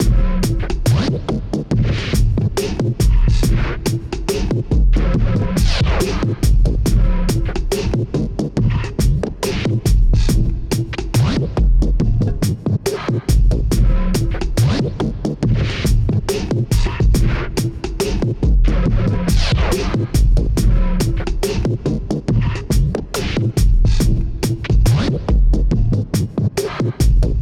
drum4.wav